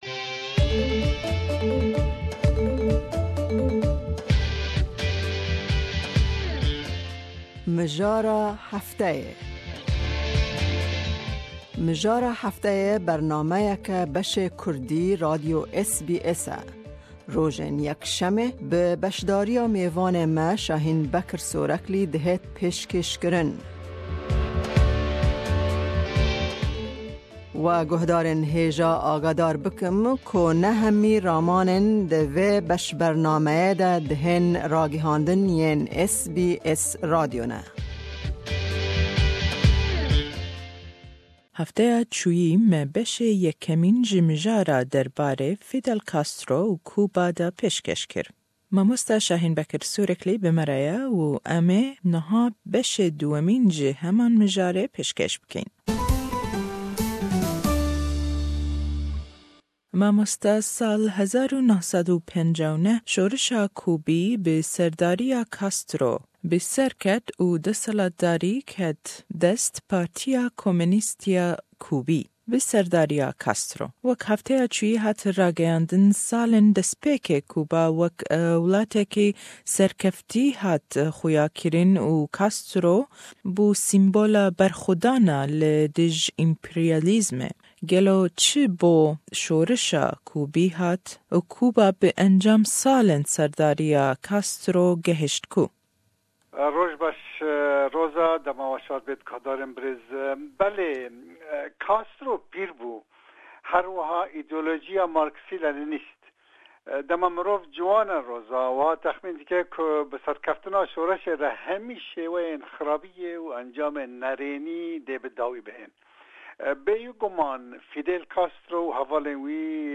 Ji beshê 2emîn de li hevpeyvîn